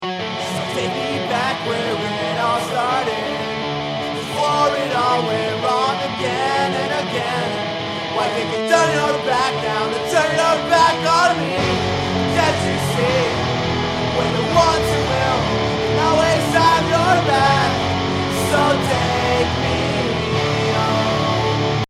getting vocals to fit, first home recording
Hey guys, Could anyone give me some hints or tips on how to get my vocals fit better in the mix? i'm using a little reverb and boosted mids a bit All help is appreciated as i really need this to work out.